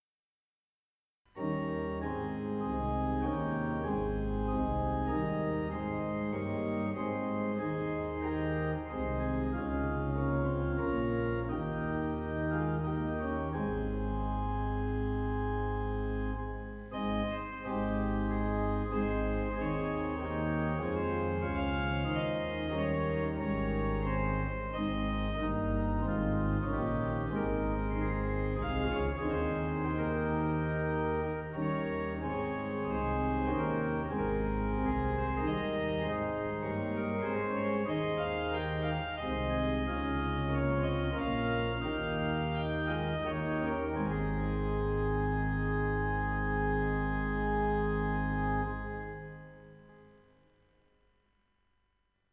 Hereford Cathedral Organ (Sample Set), used under license from Lavender Audio